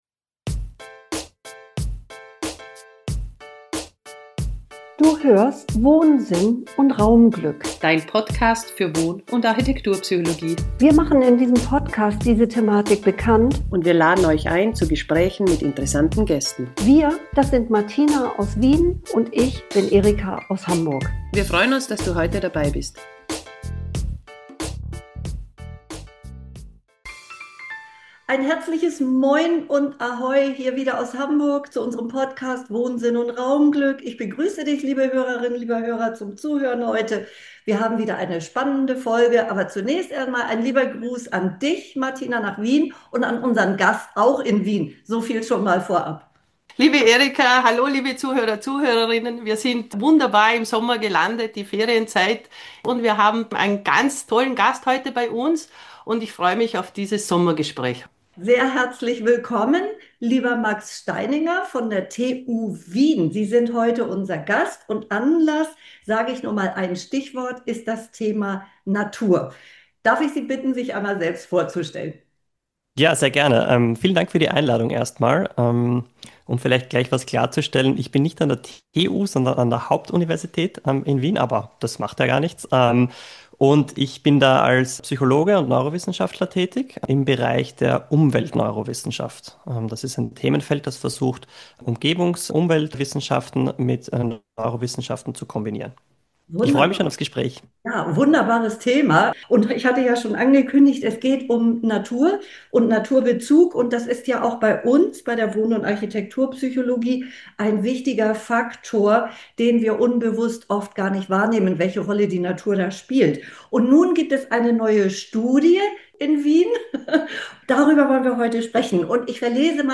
Wieder ein tolles und informatives Gespräch!